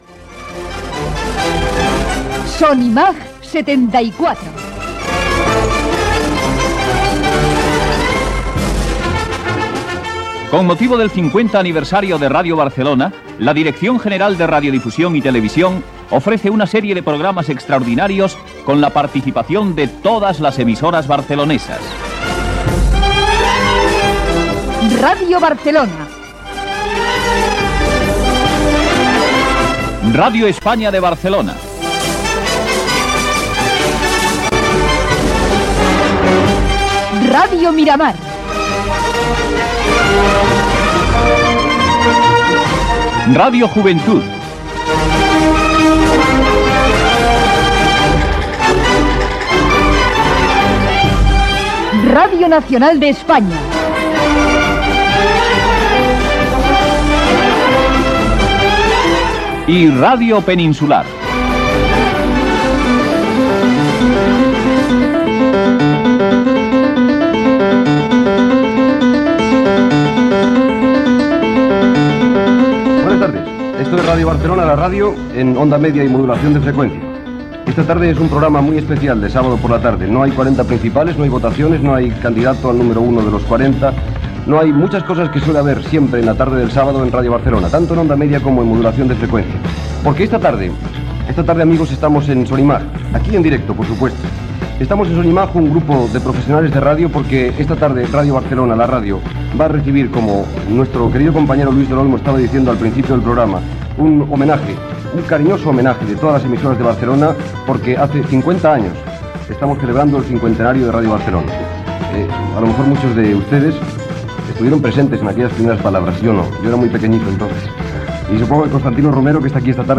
Careta del programa especial
Entreteniment